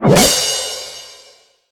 prize_stars_explosion_01.ogg